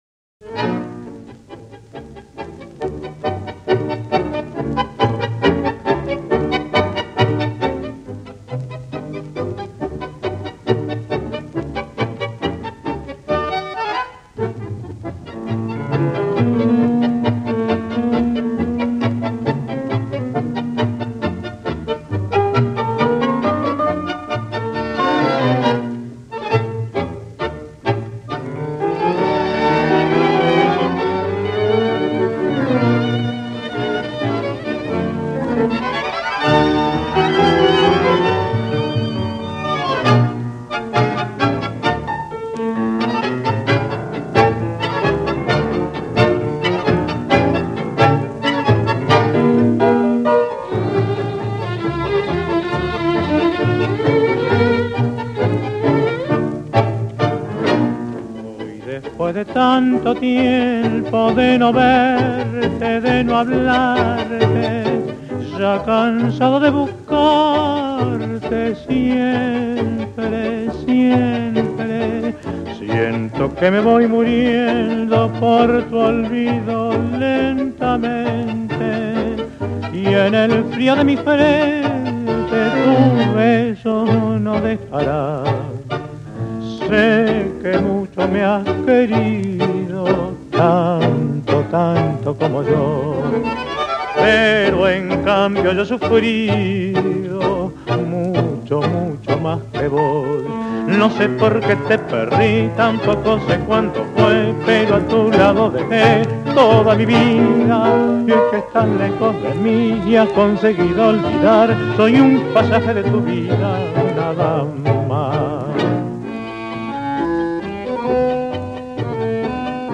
I adore this tango!